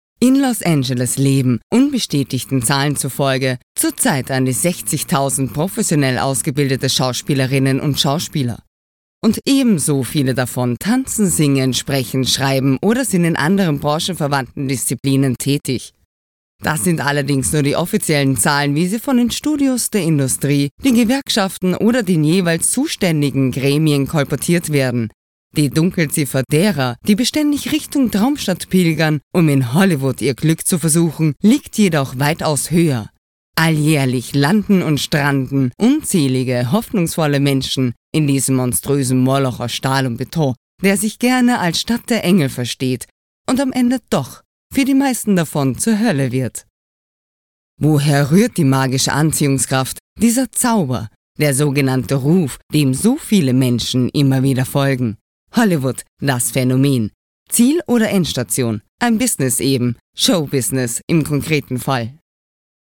Sprecherin, Synchronsprecherin